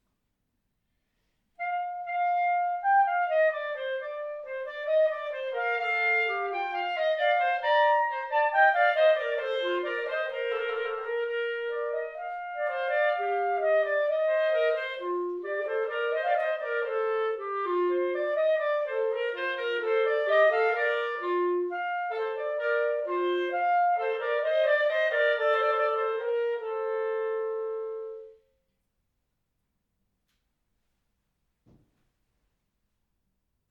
Besetzung: 2 Klarinetten